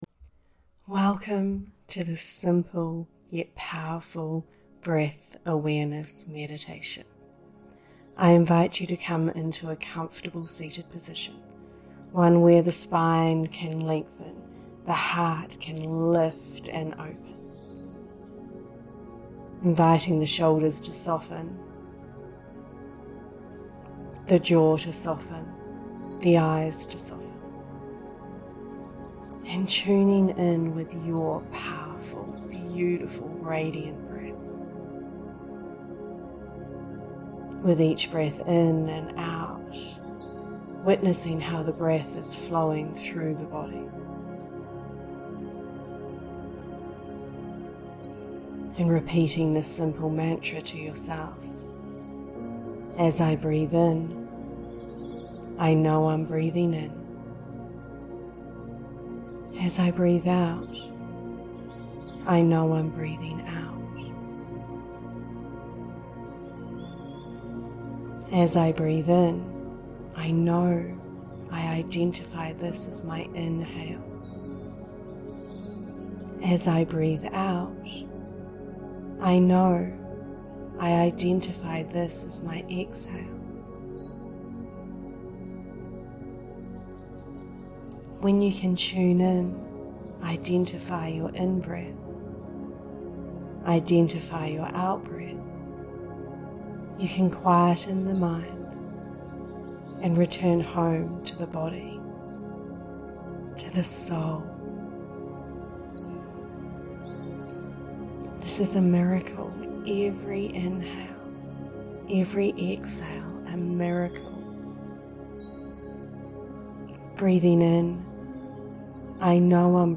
BREATH AWARENESS MEDITATION
Join me for a guided meditation as we allow the power of awareness of the breath draw us into this beautiful moment.
BreathAwarenessMeditation.mp3